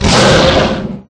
Monster1.ogg